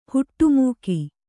♪ huṭṭu mūki